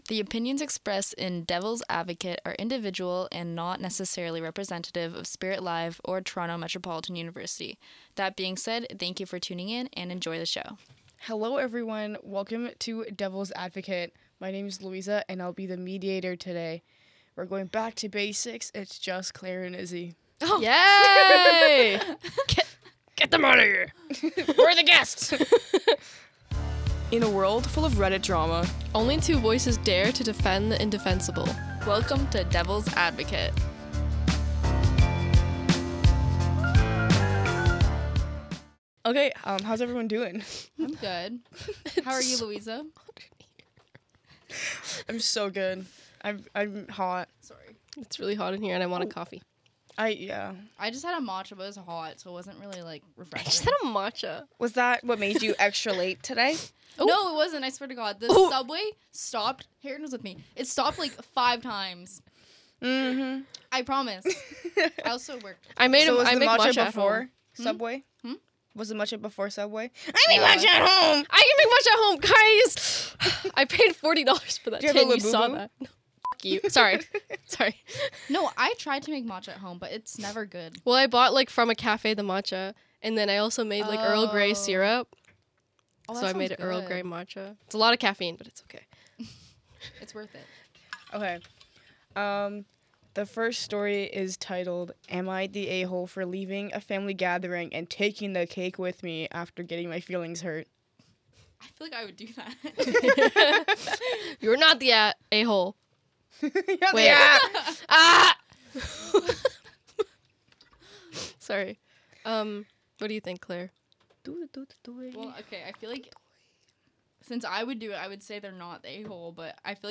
Each week three hosts will debate and discuss a Reddit post from the thread “Am I an A-hole?”. These debates will be between two hosts, one against and one for, while the third host reads, moderates and acts as a commentator of the discussion.